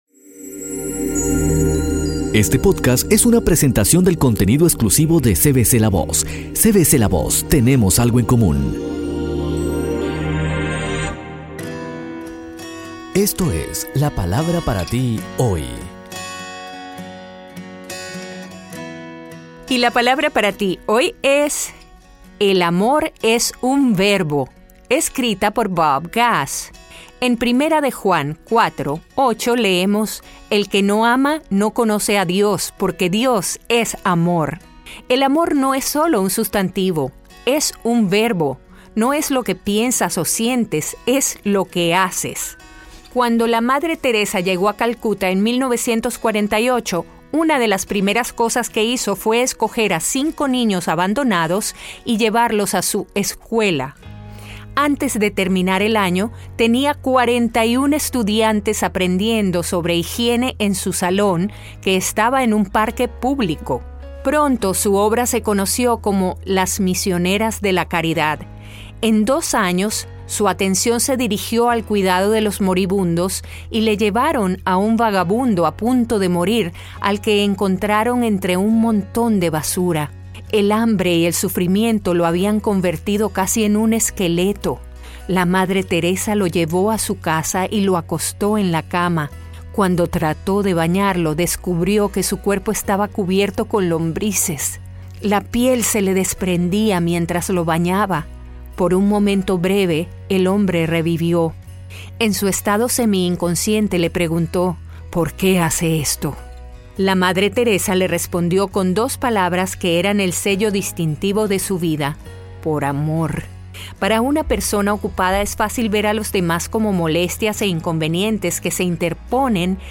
Disfruta de este nuevo podcast de La Palabra para ti hoy, escrito por Bob Gass y en la voz de nuestra Elluz Peraza. Reflexiones cortas e impactantes.